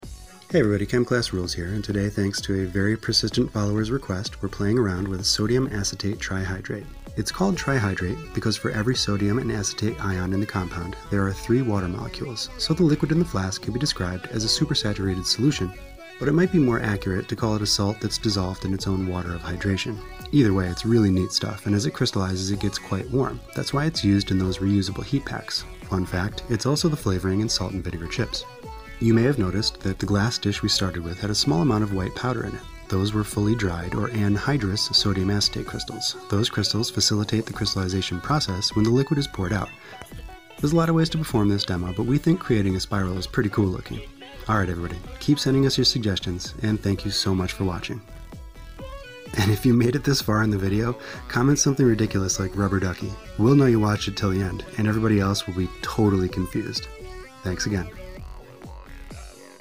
throwback to season 2 - making a sodium acetate spire. be nice, i talked slow back then.